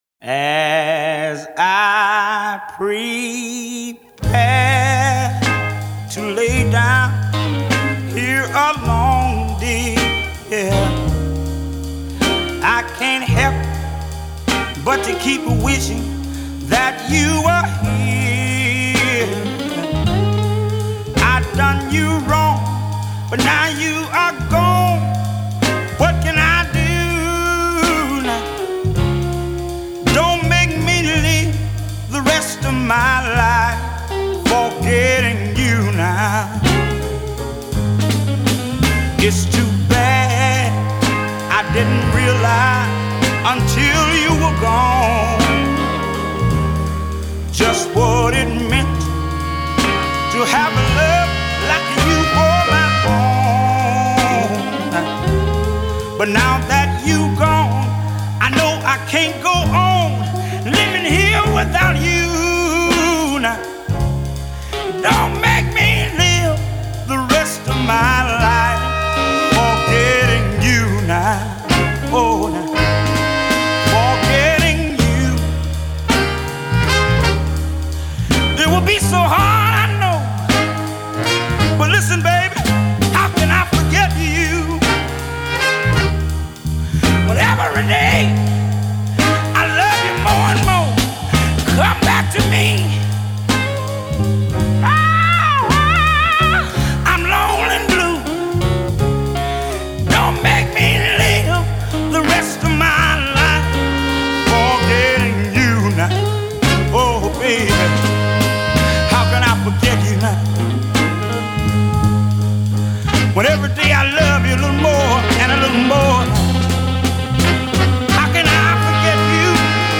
a half midnight oil burner/barnstormer